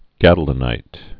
(gădl-ə-nīt)